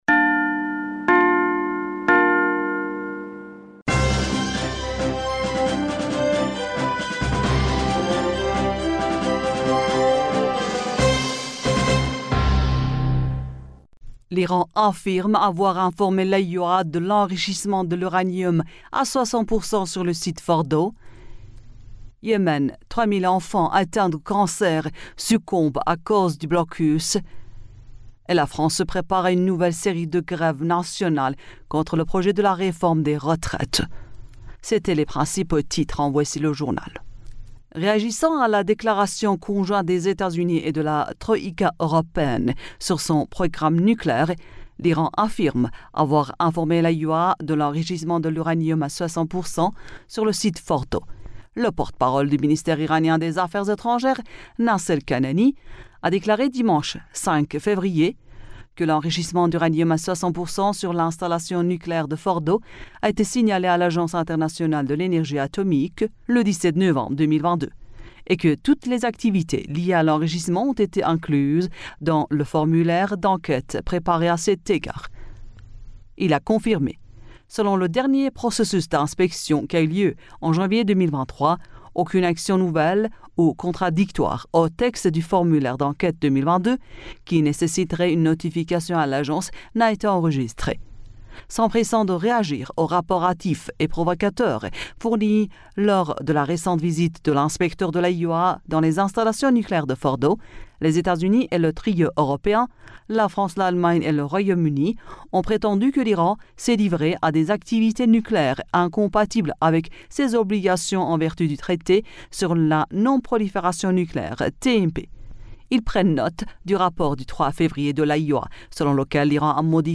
Bulletin d'information du 06 Février